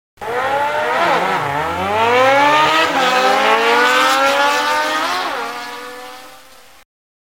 bikeSound.mp3